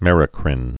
(mĕrə-krĭn, -krīn, -krēn)